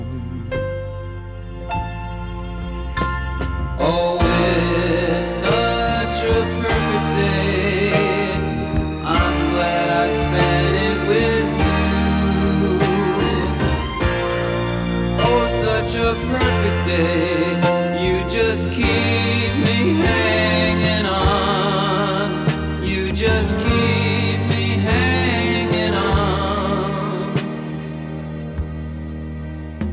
• Pop
Its verse is a circle of fifths progression in Bb minor.